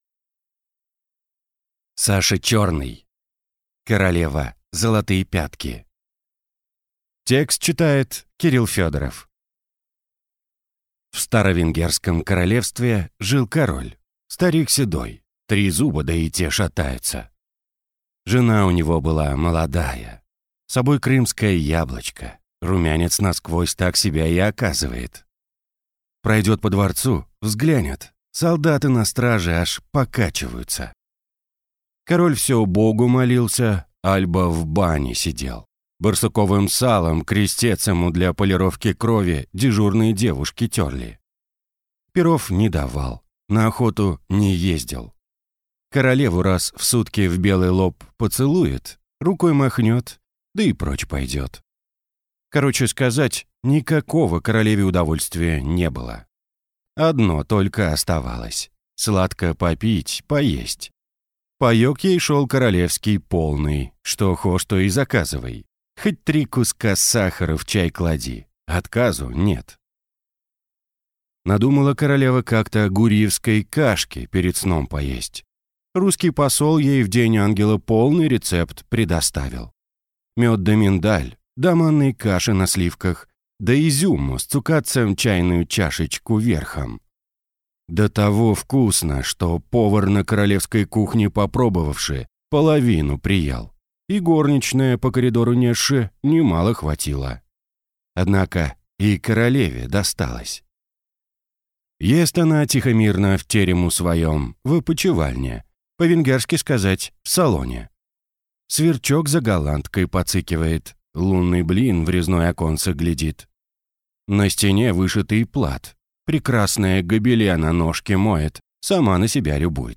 Аудиокнига Королева – золотые пятки | Библиотека аудиокниг